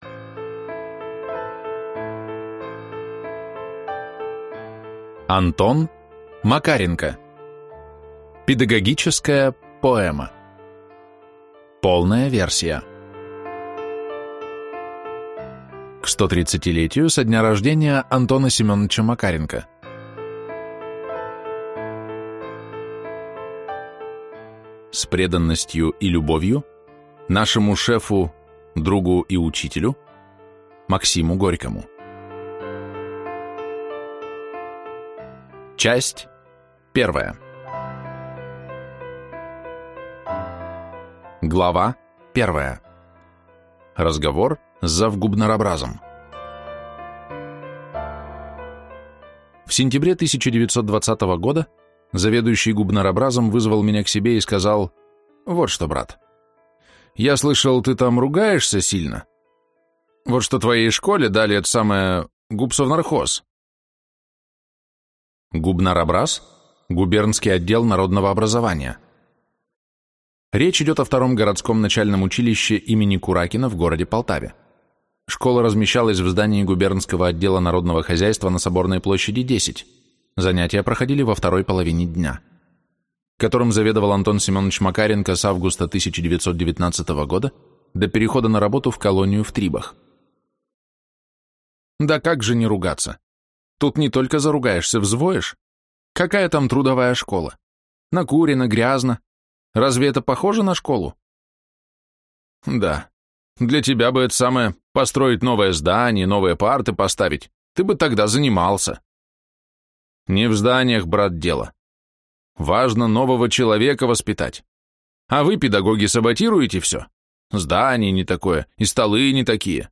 Аудиокнига Педагогическая поэма. Полная версия | Библиотека аудиокниг